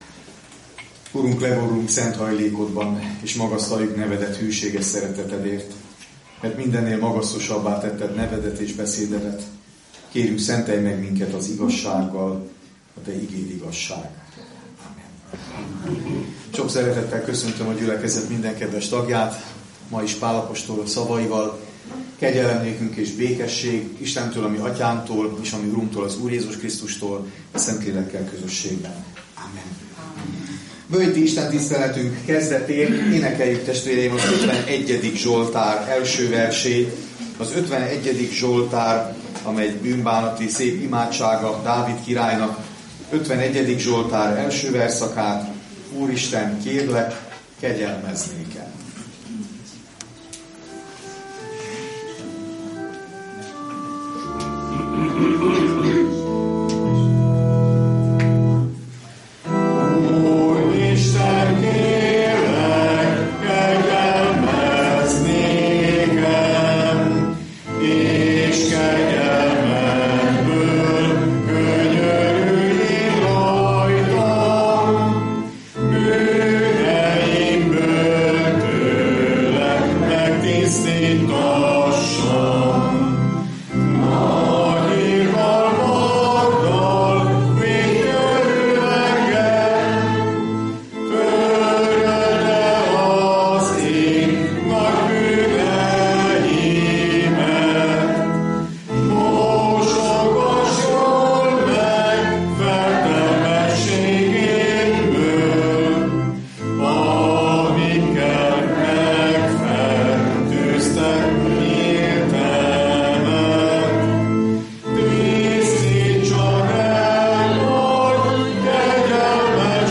Hajdúböszörmény Kálvin téri Református Egyházközség